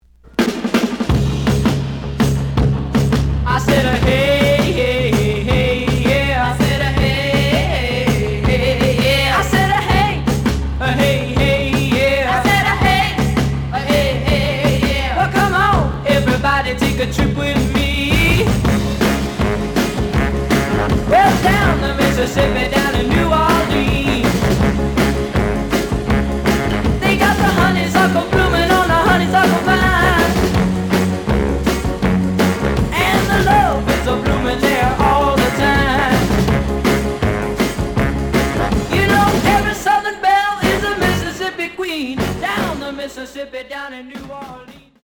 試聴は実際のレコードから録音しています。
●Genre: Rock / Pop